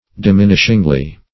diminishingly - definition of diminishingly - synonyms, pronunciation, spelling from Free Dictionary Search Result for " diminishingly" : The Collaborative International Dictionary of English v.0.48: Diminishingly \Di*min"ish*ing*ly\, adv. In a manner to diminish.
diminishingly.mp3